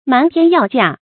瞞天要價 注音： ㄇㄢˊ ㄊㄧㄢ ㄧㄠˋ ㄐㄧㄚˋ 讀音讀法： 意思解釋： 指無限度地要高價 出處典故： 《人民戲劇》1978年第4期：「泰州一丟，你們到哪兒去占山為王？